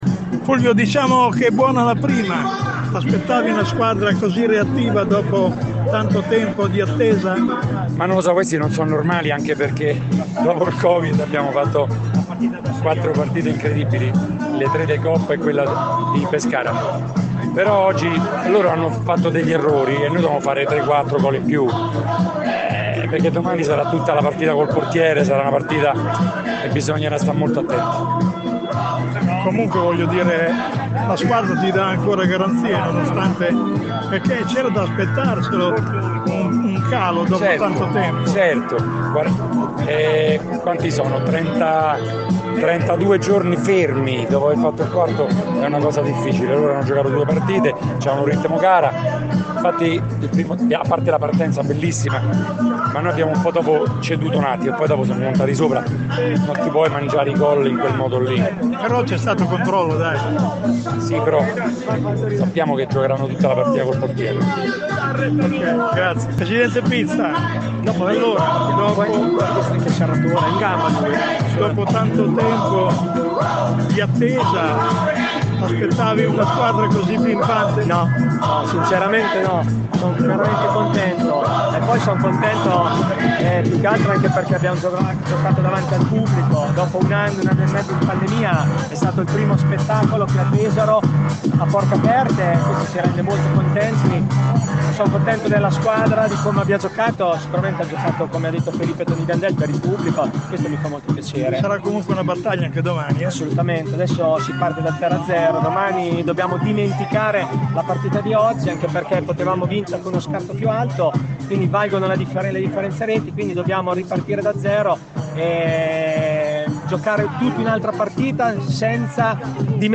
Con un perentorio 4 a 1 la squadra biancorossa, si aggiudica il primo match della semifinale scudetto, ai danni della Feldi Eboli. Le nostre interviste a